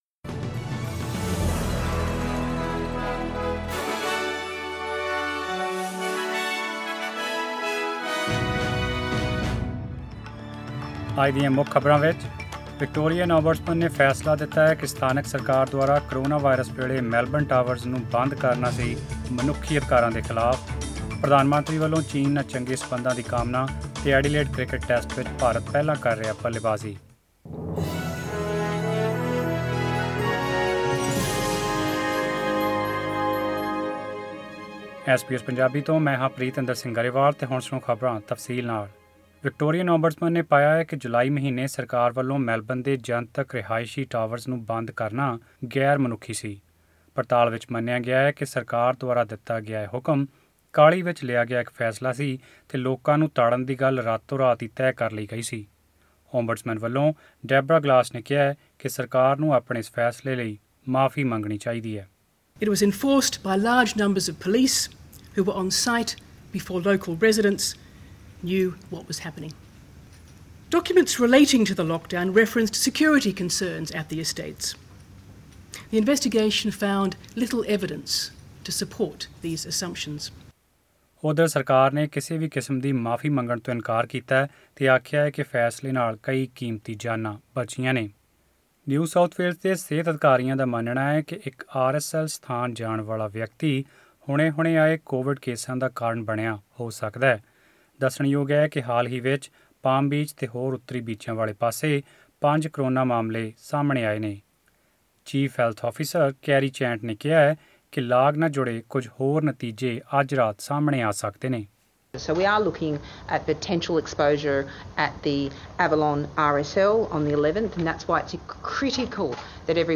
Australian News in Punjabi: 17 December 2020